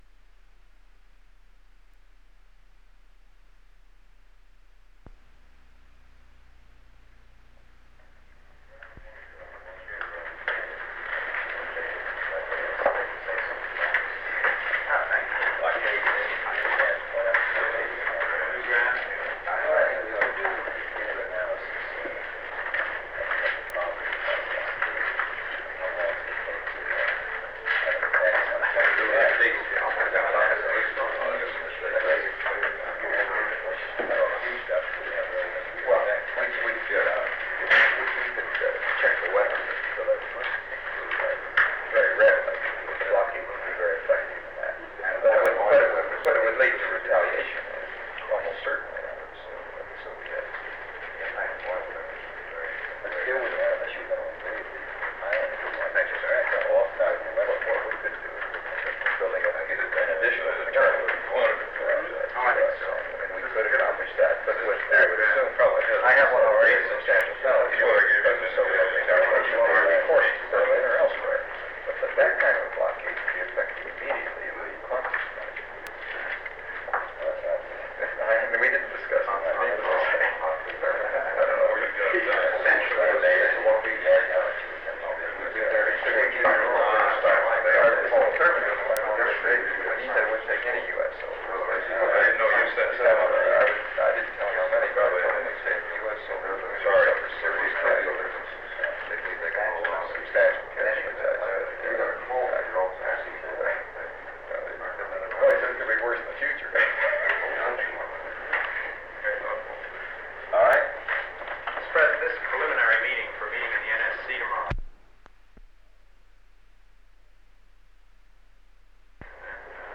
Secret White House Tapes | John F. Kennedy Presidency Meeting on the DOMINIC Nuclear Test Series Rewind 10 seconds Play/Pause Fast-forward 10 seconds 0:00 Download audio Previous Meetings: Tape 121/A57.